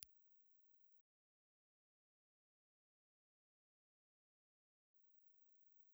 Impulse Response File:
Impulse Response file of this Tannoy ribbon microphone.
Tannoy_Large_UnID_IR.wav
Larger cardioid ribbon microphone from Tannoy